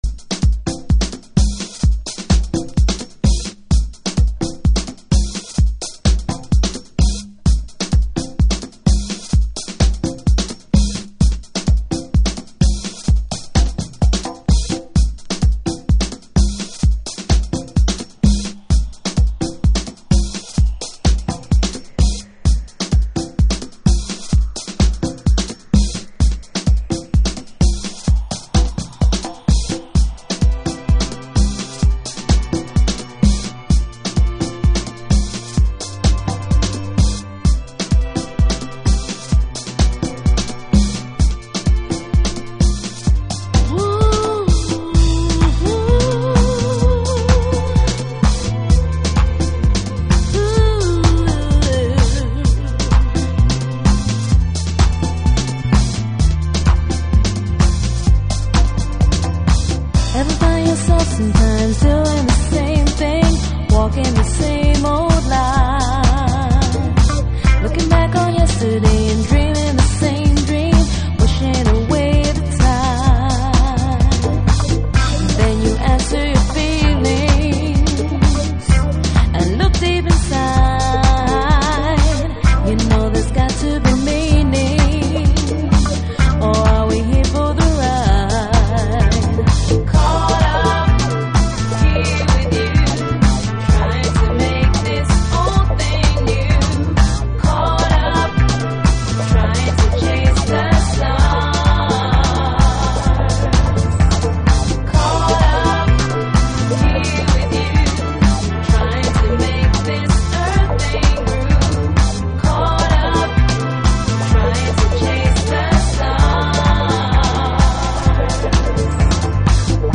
複雑に刻まれサンプリングされたビートとアコースティック群が織りなす新機軸のグルーヴ。